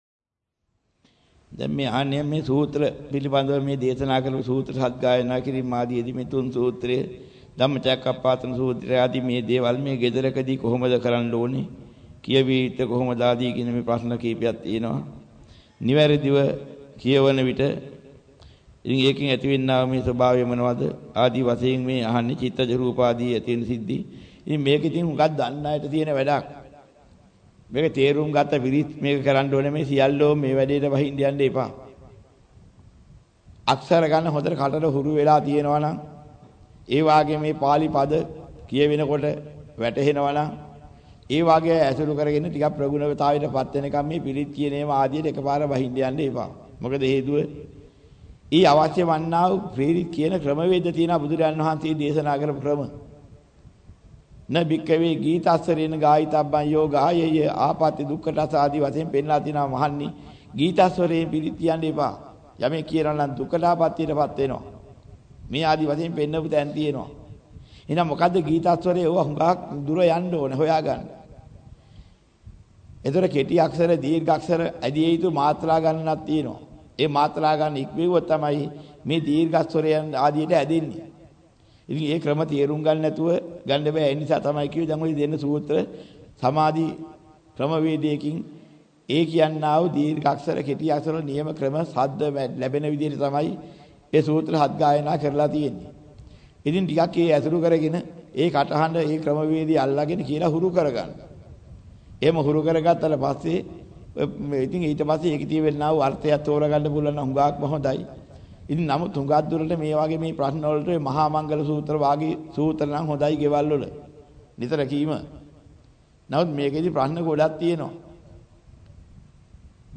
වෙනත් බ්‍රව්සරයක් භාවිතා කරන්නැයි යෝජනා කර සිටිමු 13:25 10 fast_rewind 10 fast_forward share බෙදාගන්න මෙම දේශනය පසුව සවන් දීමට අවැසි නම් මෙතැනින් බාගත කරන්න  (6 MB)